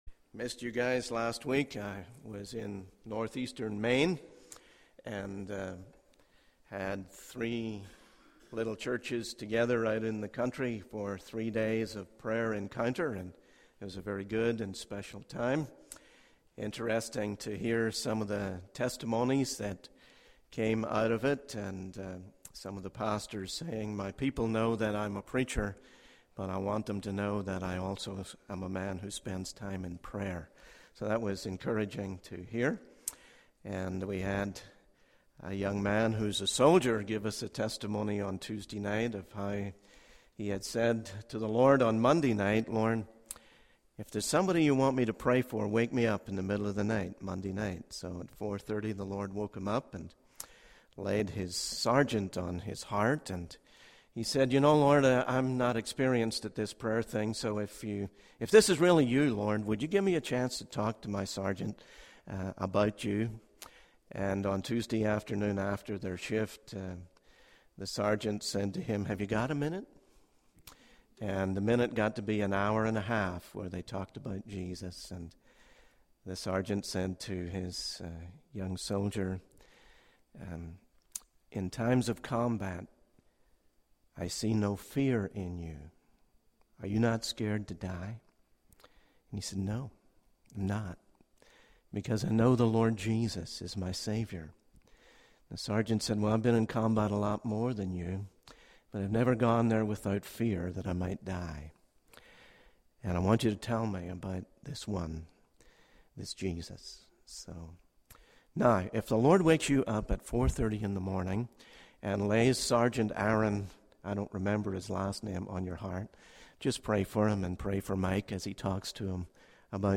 In this sermon, the preacher emphasizes the importance of maintaining a passionate and living relationship with God. He encourages believers to stand together, stay confident, and seek to fulfill the will of God.